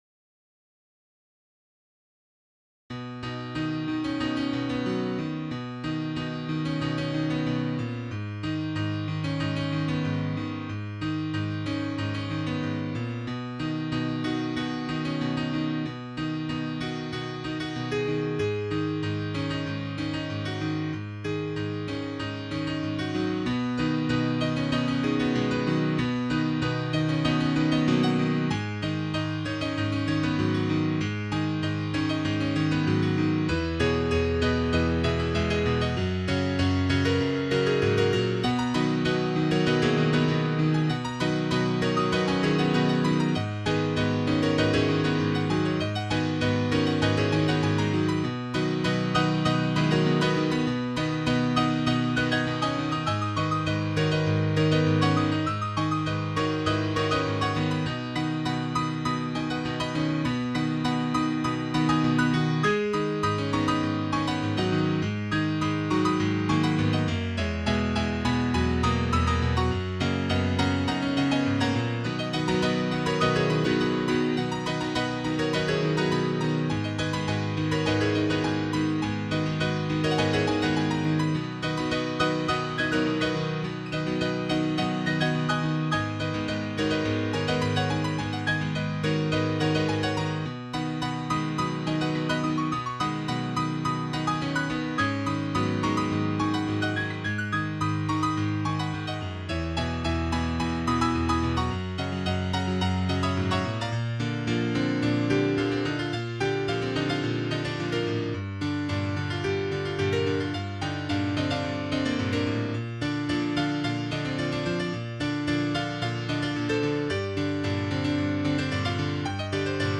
Title Out in the Rain Opus # 191 Year 0000 Duration 00:03:28 Self-Rating 4 Description A sprightly little thing.
Your choice. mp3 download wav download Files: mp3 wav Tags: Solo, Piano Plays: 1781 Likes: 0